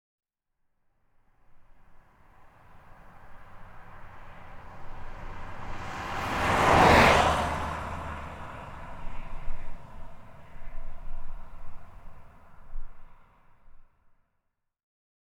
PIANETA GRATIS - Audio/Suonerie - Mezzi di Trasporto - Auto 07
car-passing-1.mp3